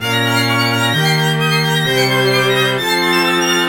自编的古典弦乐循环曲
Tag: 130 bpm Electronic Loops Strings Loops 637.76 KB wav Key : Unknown